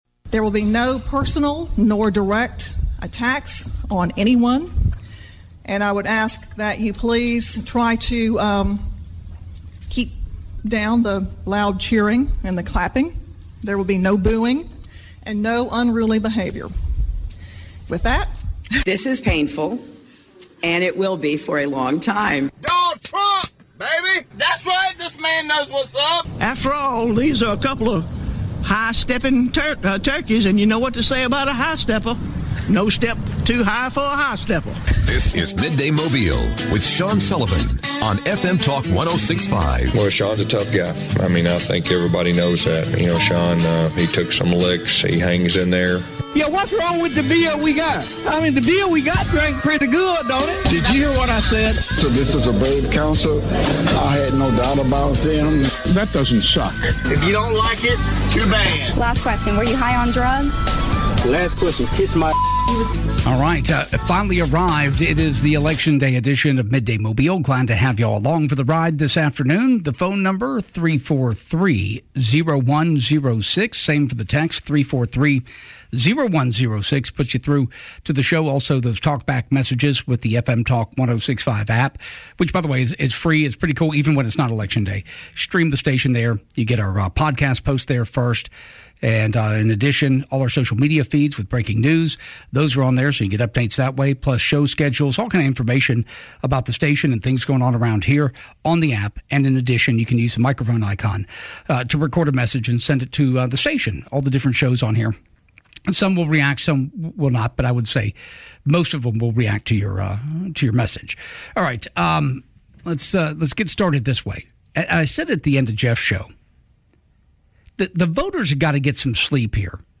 Election talk with listeners